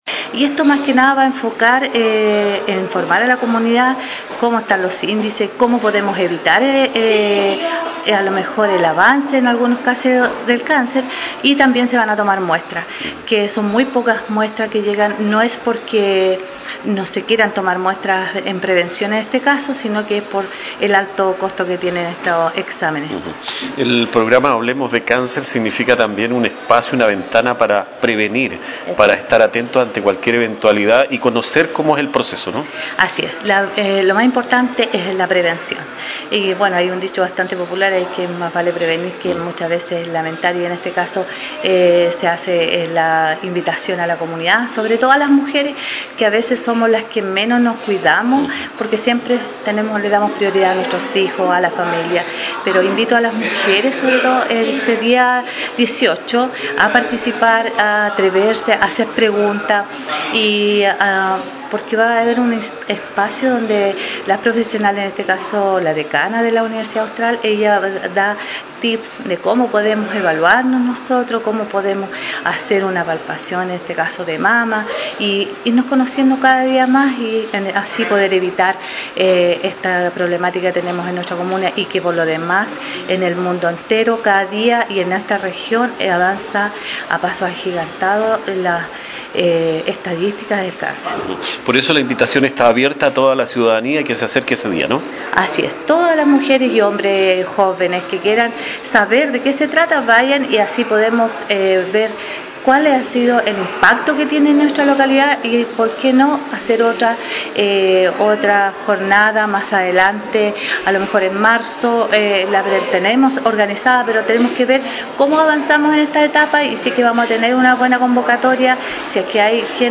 La  Concejal Mónica Patiño Fernández,  integrante de la Agrupación «Andrea Quezada», hace un llamado a las organizaciones sociales y vecinos de Malalhue a unirse a esta iniciativa que busca sensibilizar y educar sobre la prevención y el acompañamiento a personas con cáncer, reiterando que la participación de vecinos y vecinas es fundamental para fortalecer el apoyo y la toma de conciencia en torno a esta enfermedad.